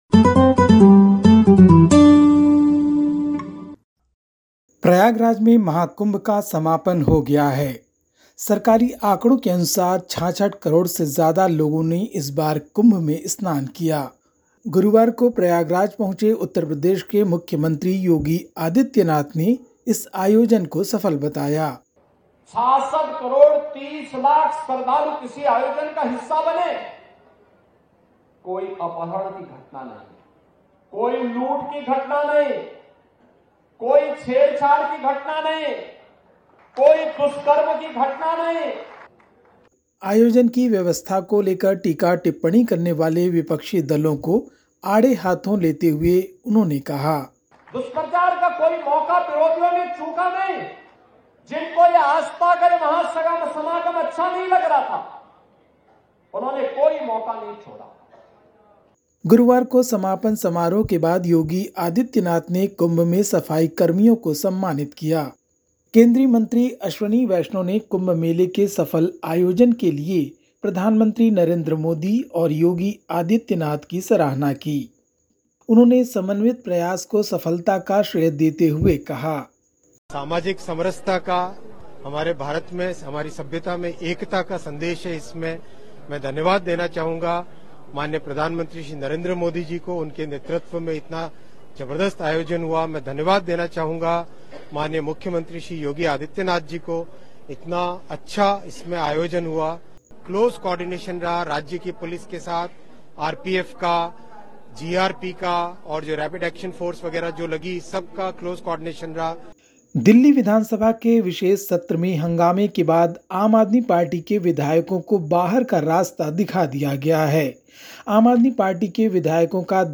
India report: Uttar Pradesh CM Yogi Adityanath praises Maha Kumbh as a success